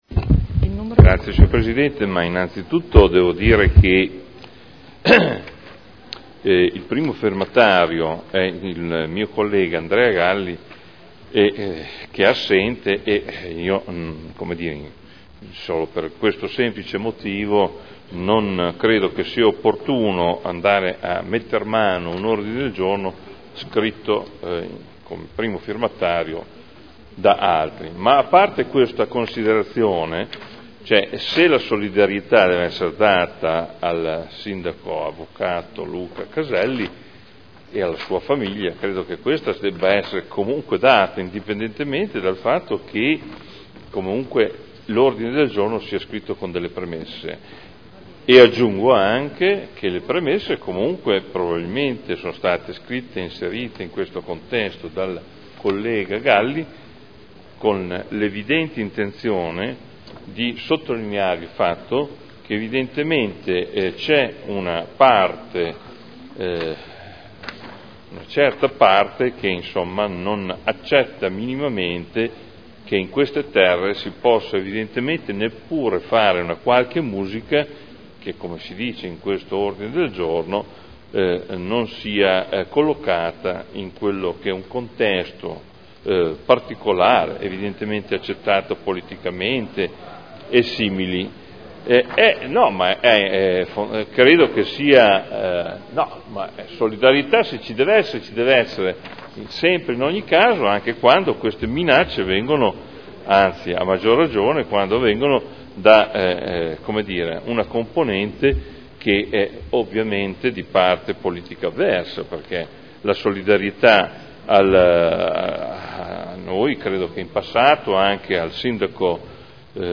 Adolfo Morandi — Sito Audio Consiglio Comunale
Seduta del 27 febbraio 2012